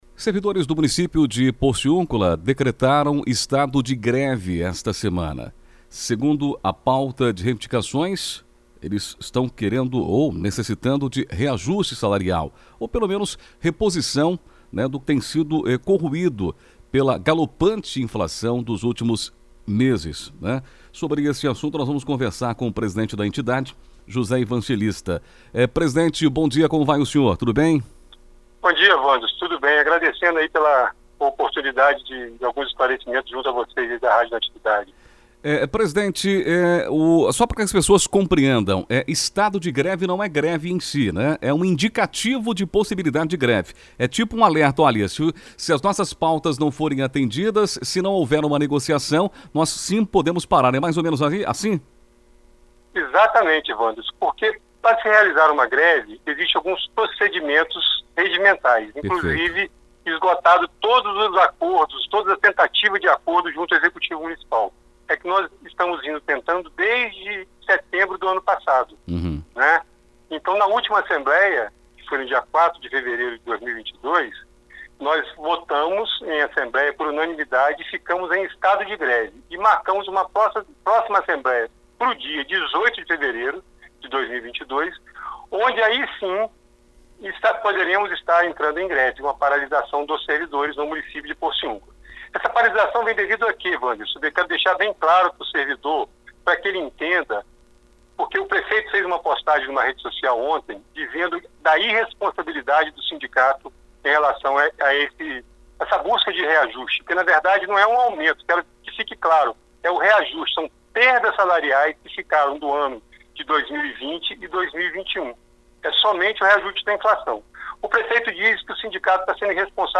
Servidores municipais de Porciúncula deflagram estado de greve – OUÇA A ENTREVISTA
ENTREVISTA-GREVE-PORCIUCULA.mp3